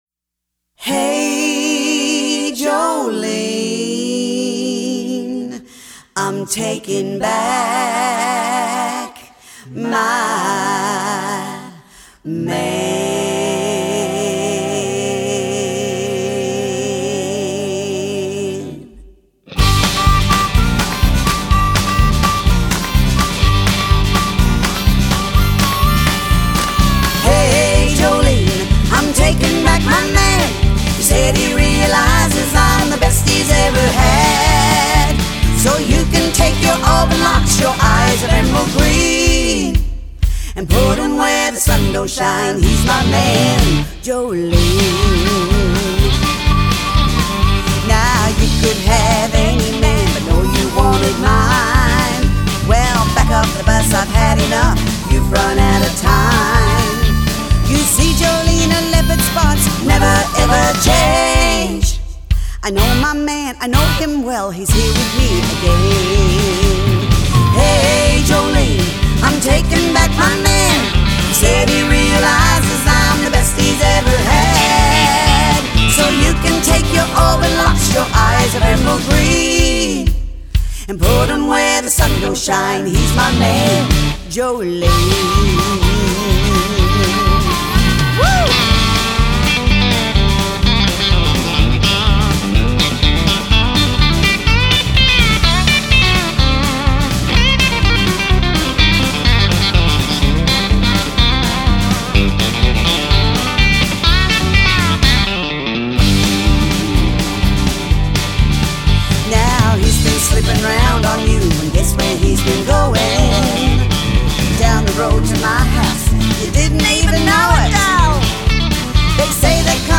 This infectious country song
I knew I wanted it to be right up there tempo wise
harmonica solo